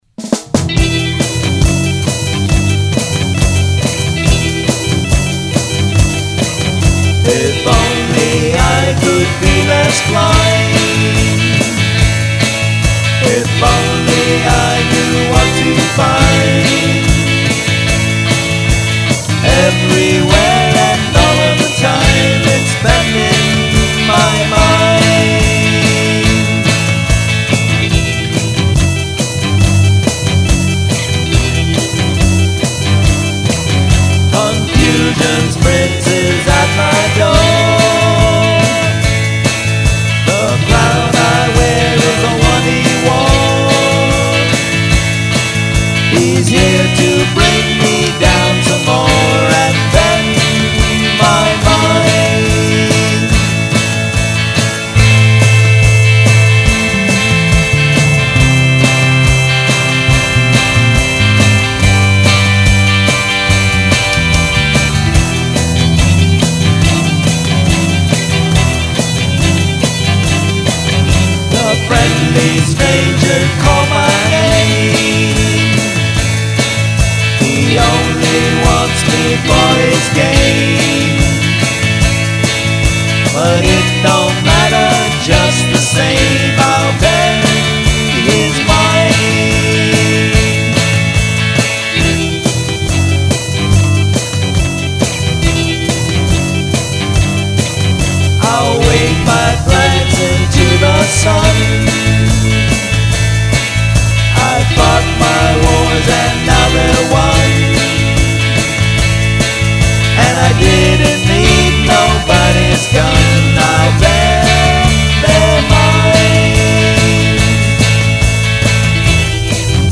Songs 7-12 (SIDE B - Psychedelia):